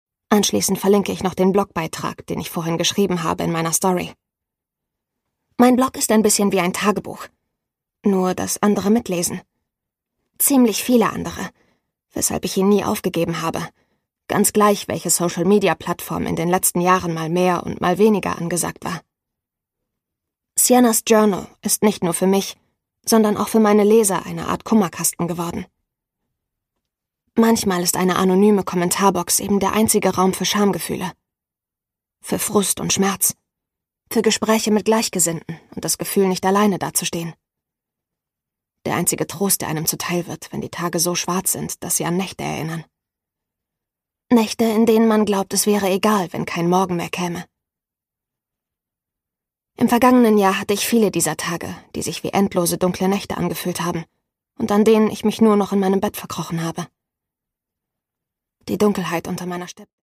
Saskia Hirschberg: Will You Hold My Hand? (Ungekürzte Lesung)
Produkttyp: Hörbuch-Download